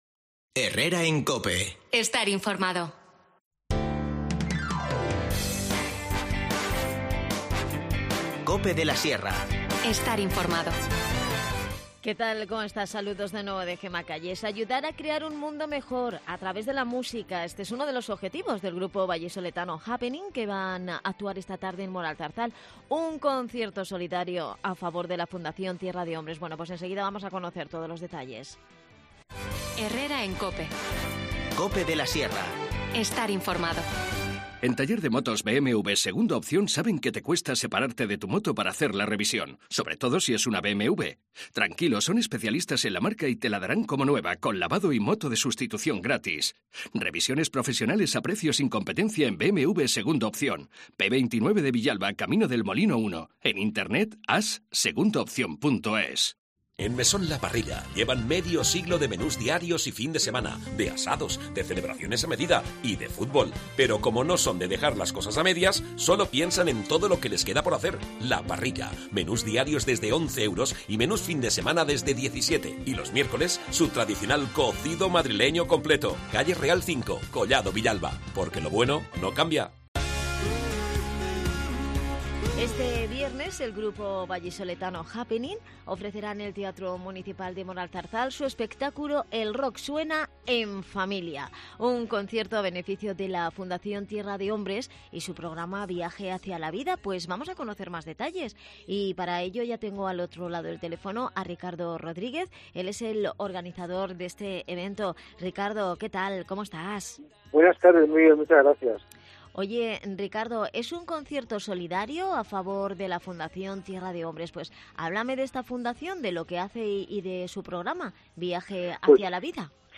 Charlamos en el programa